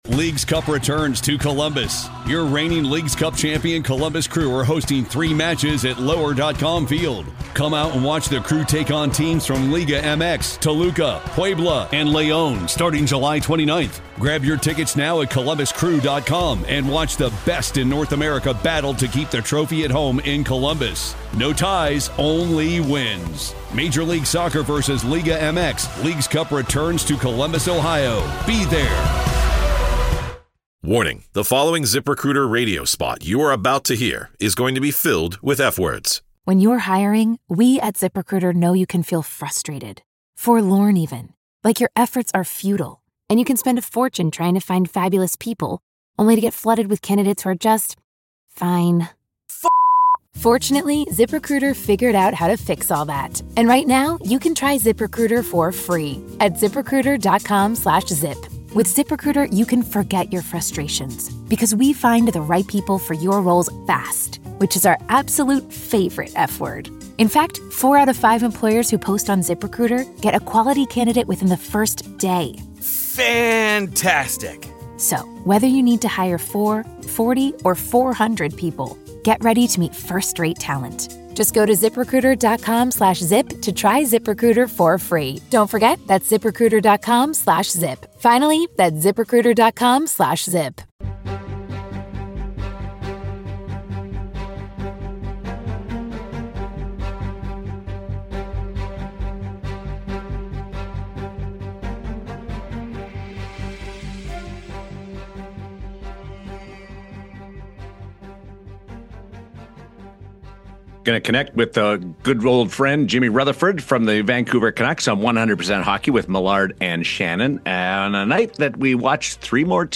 From reports of Rick Tocchet’s confirmed departure to speculation around core players like Quinn Hughes and Brock Boeser, Rutherford gives us the latest, in his trademark direct style. We also dive into the rumors surrounding a rift between J.T. Miller and Elias Pettersson and whether there’s more to the story than fans realize. It’s a wide-ranging conversation on the state of the franchise, the media storm around it, and where the Canucks go from here.